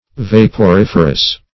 Search Result for " vaporiferous" : The Collaborative International Dictionary of English v.0.48: Vaporiferous \Vap`o*rif"er*ous\, a. [L. vaporifer; vapor + ferre to bear.]
vaporiferous.mp3